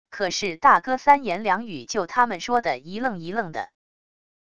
可是大哥三言两语就他们说的一愣一愣的wav音频生成系统WAV Audio Player